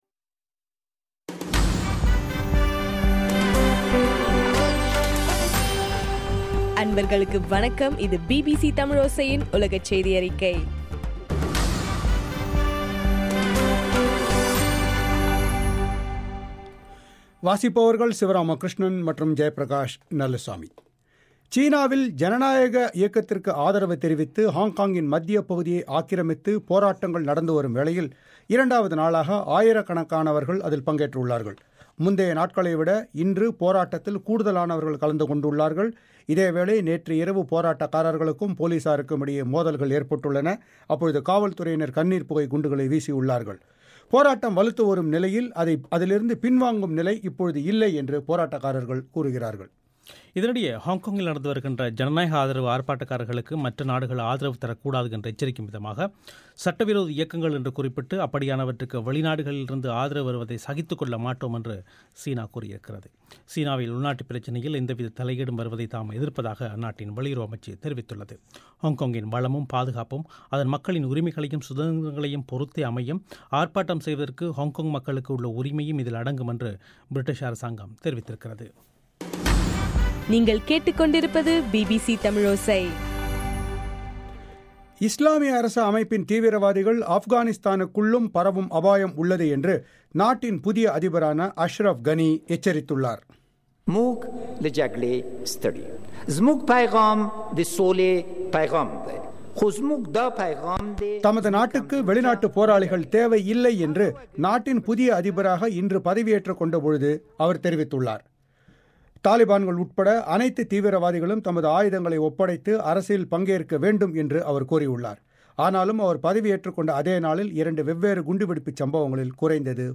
செப்டம்பர் 29 பிபிசி தமிழோசை செய்தி அறிக்கை